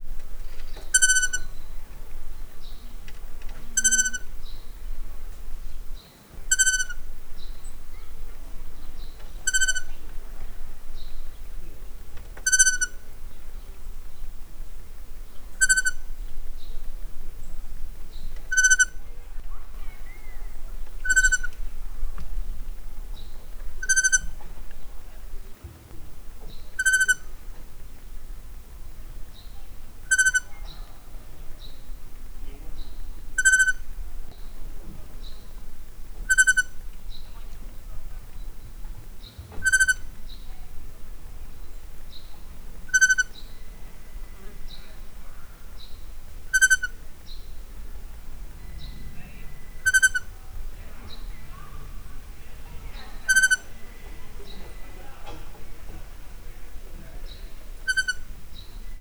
retifulesbagoly00.59.wav